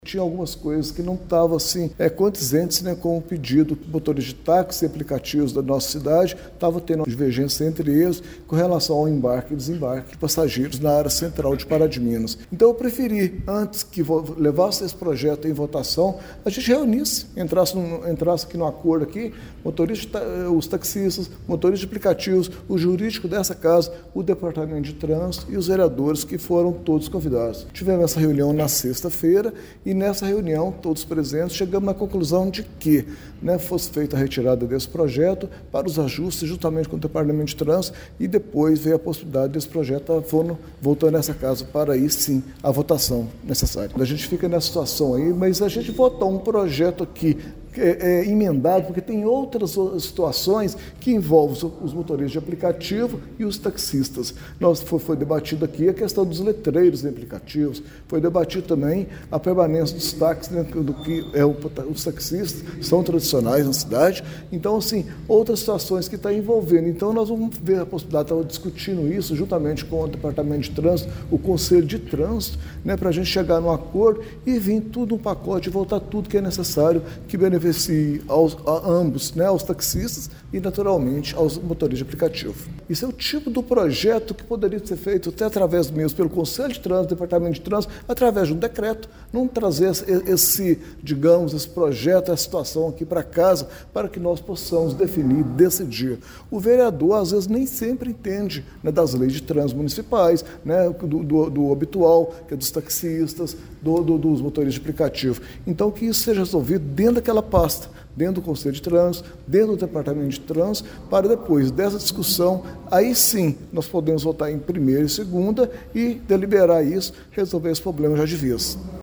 O parlamentar também falou sobre esta falta de entendimento entre taxistas e motoristas de aplicativo, apesar de as partes afirmarem que mantém bom relacionamento, mas no que se refere a este projeto isso não se reflete: